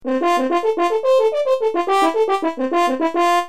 Звук Трубы поднимает настроение